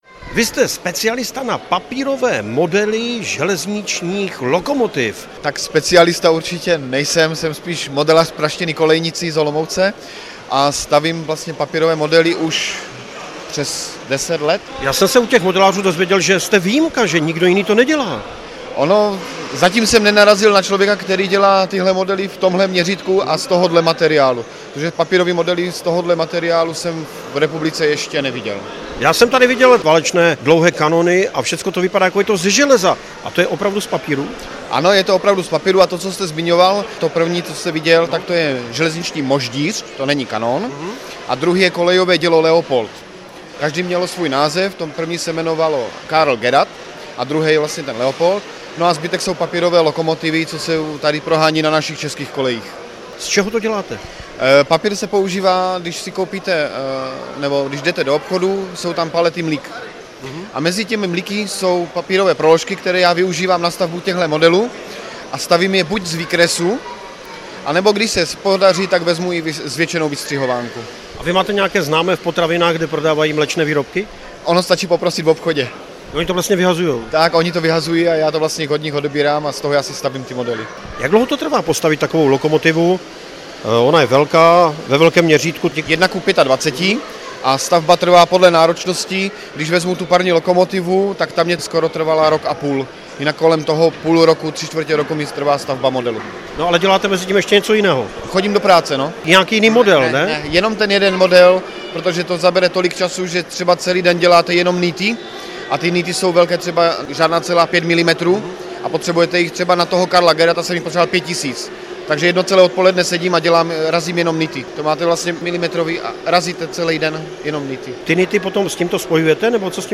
rozhovor-ceskyrozhlas.mp3